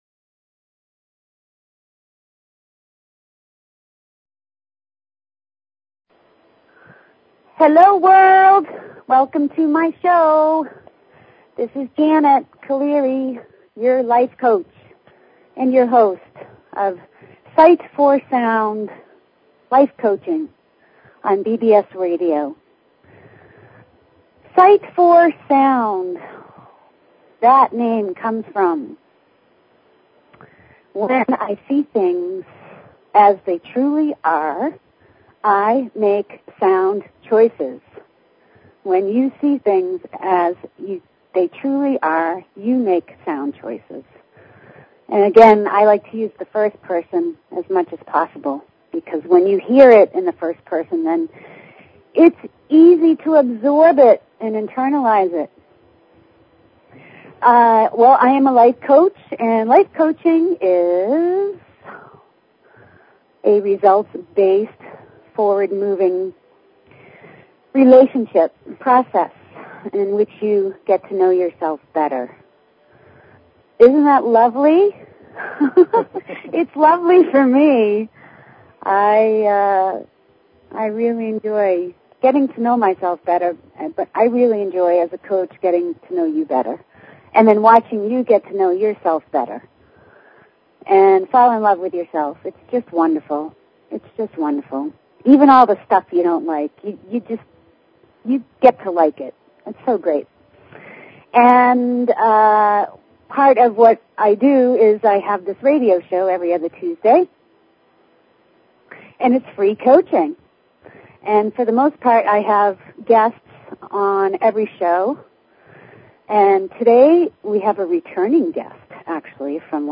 Talk Show Episode, Audio Podcast, Sight_for_Sound and Courtesy of BBS Radio on , show guests , about , categorized as
Courtesy of BBS Radio